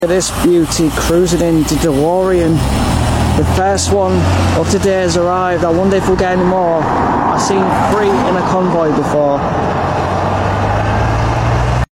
The Iconic Delorean Being Driven